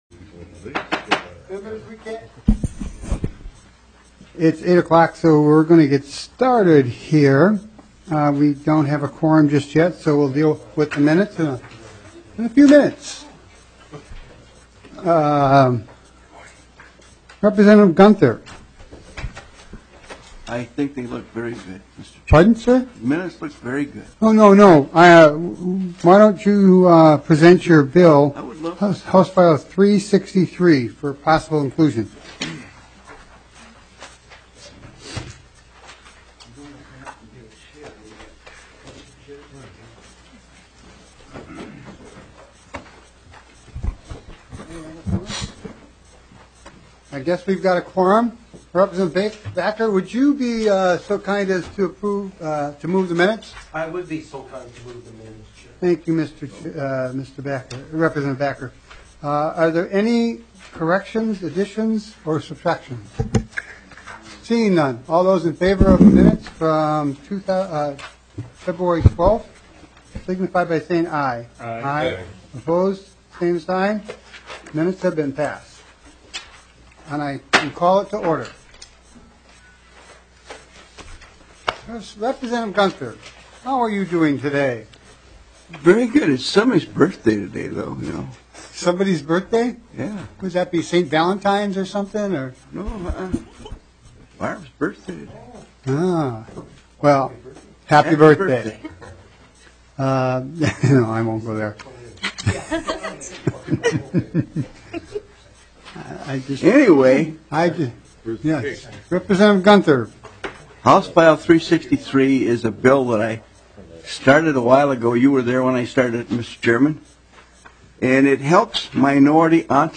HF 741 (Noor) Limiting the use of money bail for certain offenses *Evening Hearing: Room 300S at 5:00pm Bills Added: HF741 (Noor) - Money bail use for certain offenses limited.